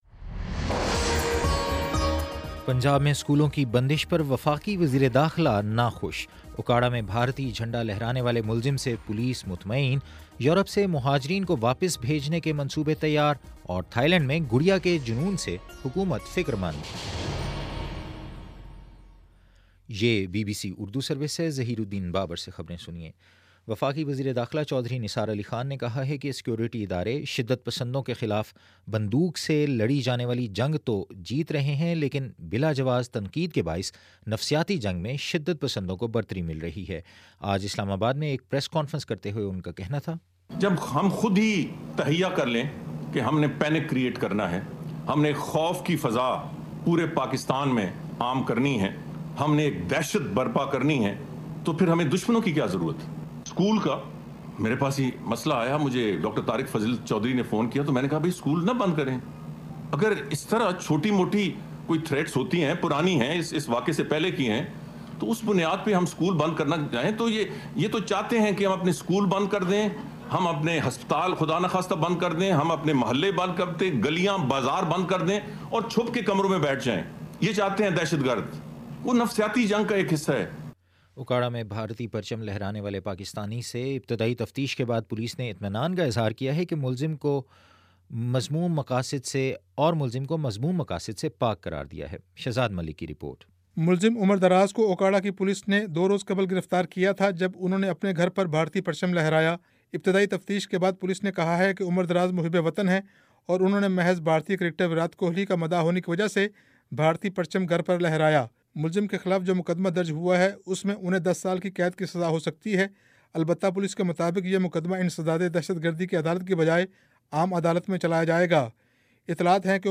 جنوری 28 : شام چھ بجے کا نیوز بُلیٹن